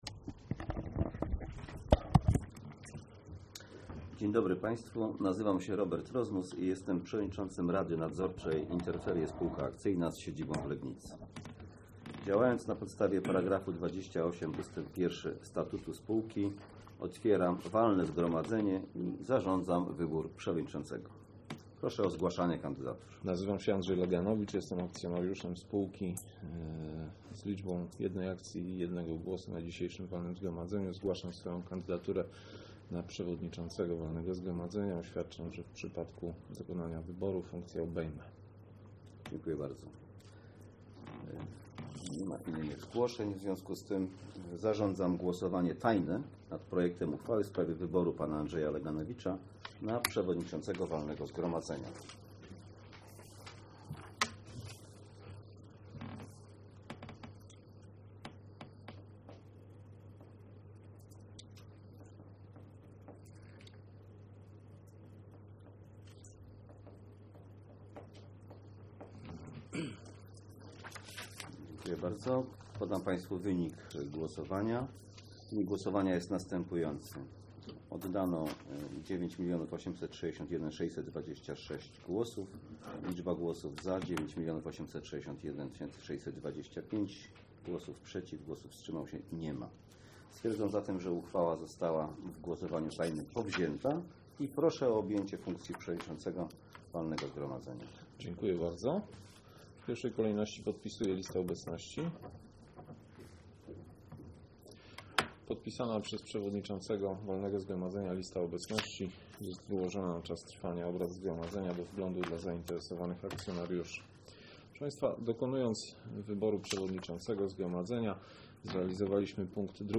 Nadzwyczajne Walne Zgromadzenie INTERFERIE S.A. 14.01.2016 r.
Nagranie NWZ